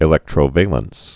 (ĭ-lĕktrō-vāləns)